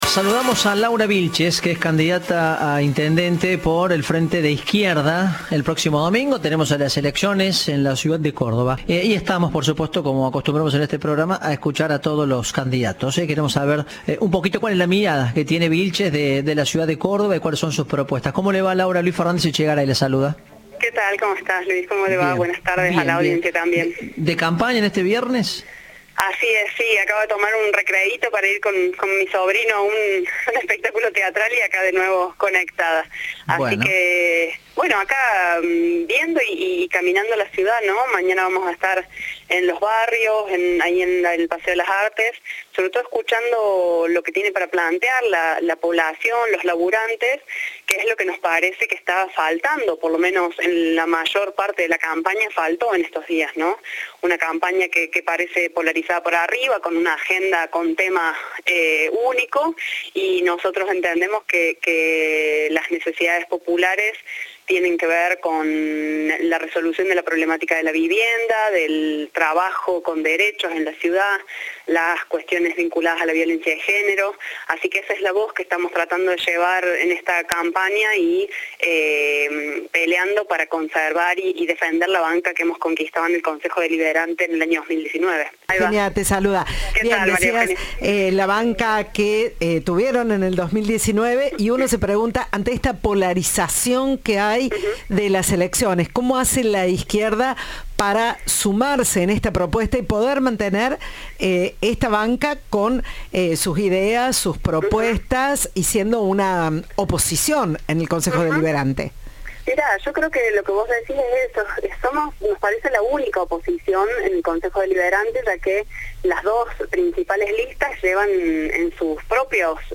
Entrevista de "Informados, al regreso".